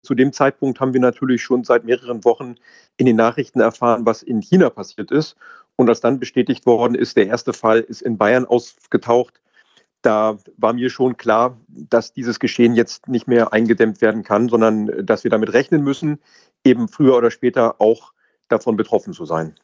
Landrat Martin Sommer über Corona
Landrat Dr. Martin Sommer